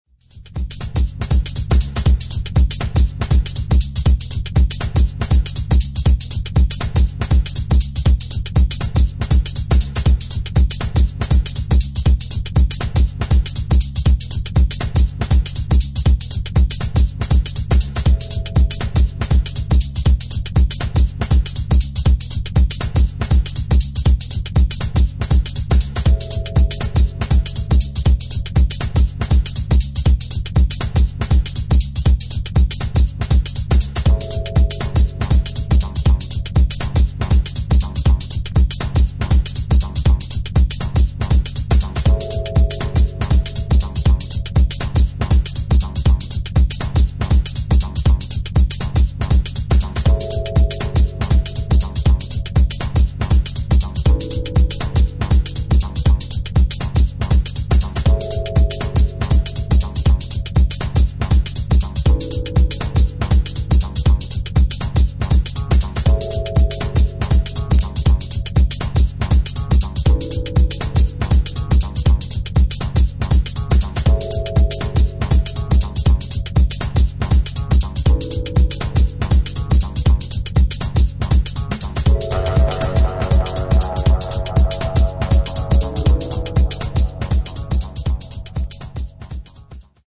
重低音のベースにトライバルなグルーヴがフロア映えする西海岸ダブ・ハウス・トラック